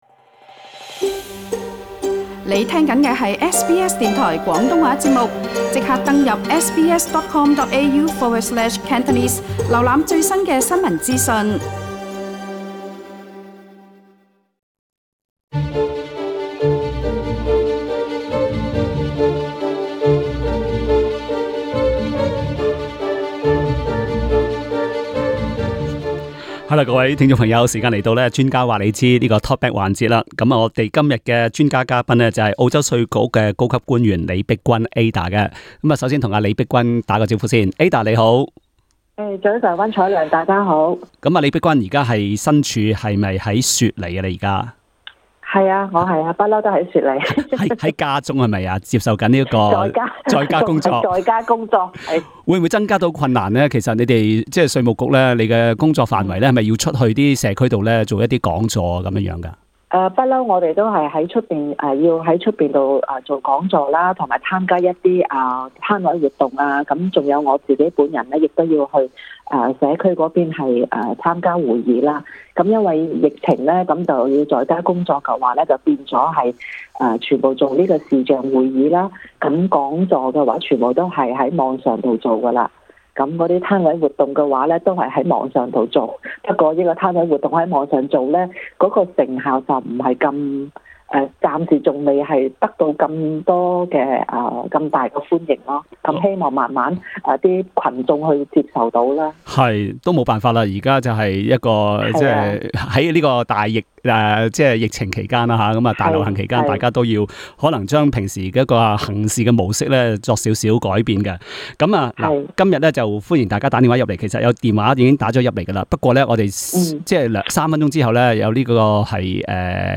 Talkback 環節